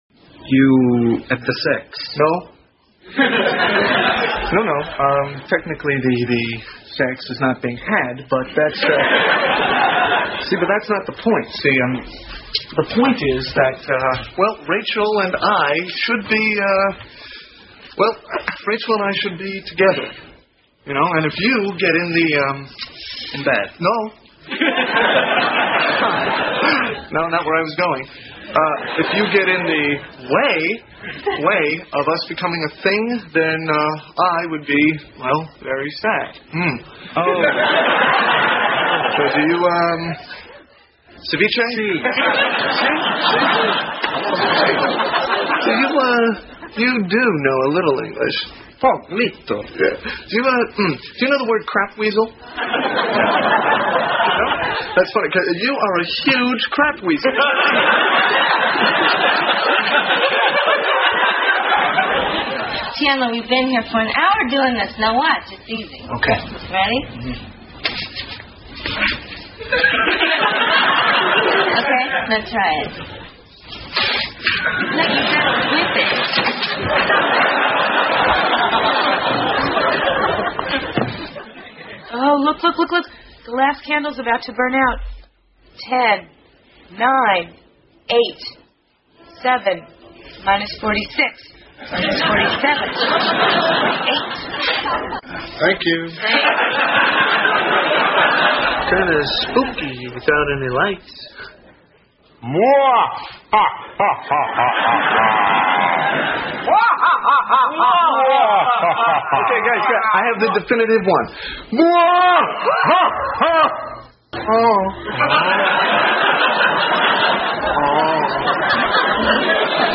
在线英语听力室老友记精校版第1季 第86期:停电(13)的听力文件下载, 《老友记精校版》是美国乃至全世界最受欢迎的情景喜剧，一共拍摄了10季，以其幽默的对白和与现实生活的贴近吸引了无数的观众，精校版栏目搭配高音质音频与同步双语字幕，是练习提升英语听力水平，积累英语知识的好帮手。